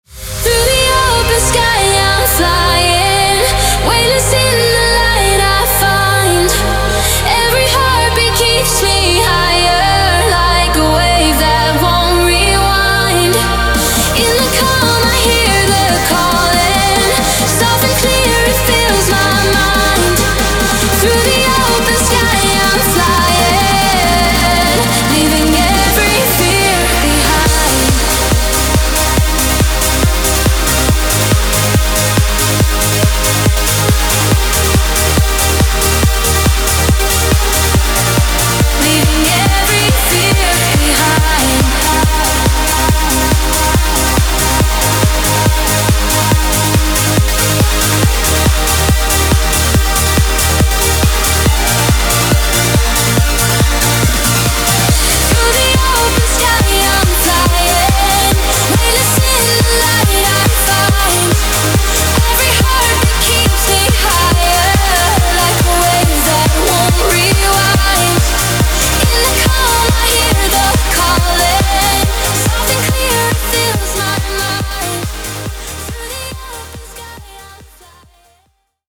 Trance Uplifting Trance Vocals
5 Midi files (Main Lead, Sub Bass, Mid Bass, Bass Pad, Pad)
1 Full wet vocal (02:29 min)
This vocal was produced in a digital environment (AI).
Style: Trance, Uplifting Trance
Tone-Scale: C Minor
BPM: 140